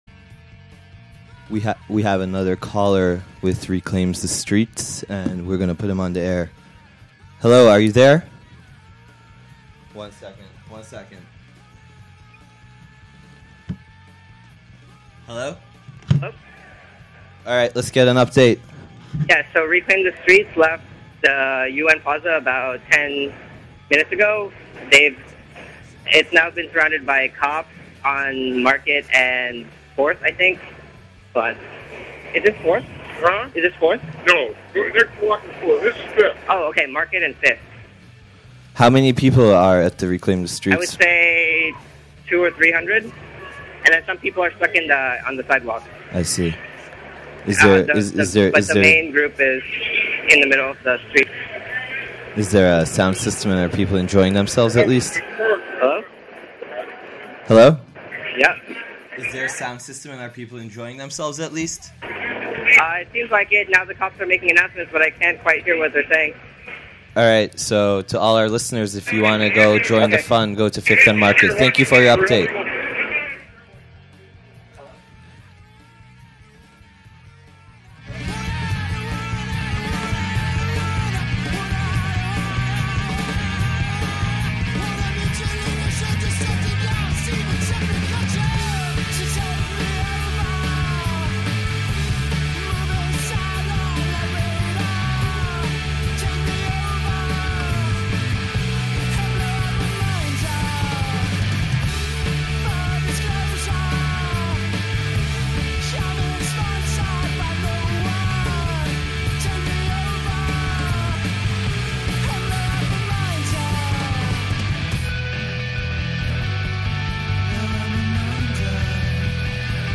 audio from the Biotech rally/march on Market St. Tues. 6/8; listen closely in background, at 1:40 minutes in, police order fully surrounded protesters to disperse, about 2:00 in announce they are under arrest - compare to police reports from 6/9 Chronicle article